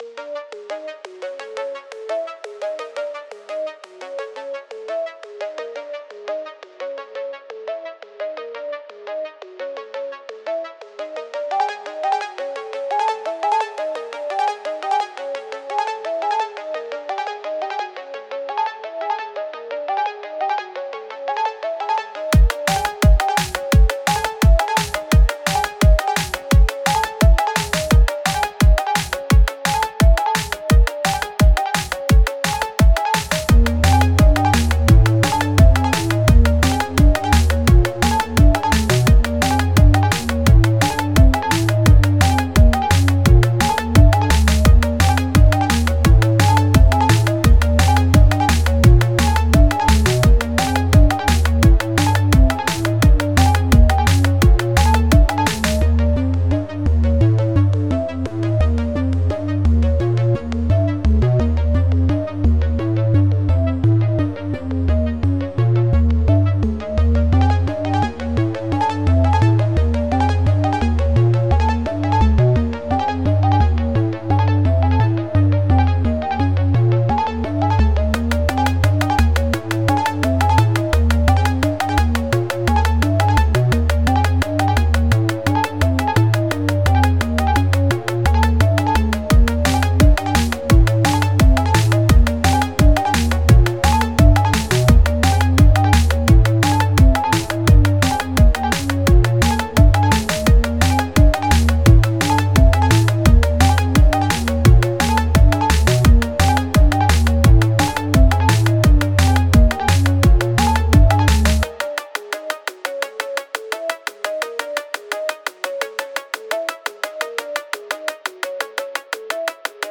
The first one is still ears pain inducing, the second one is leagues better
the pitch for each sound doesn't match methinks, you need to follow a chromatic scale so your song doesn't sound offtune the whole time